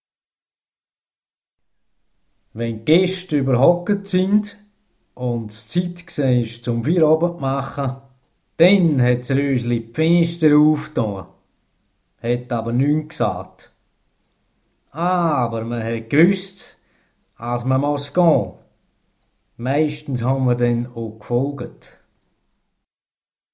• die Tonaufnahmen einiger Anekdoten aus dem „Frauenbuch“ in allen fünf Dörfern Sennwalds wie vorgesehen durchgeführt werden konnten